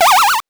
powerup_39.wav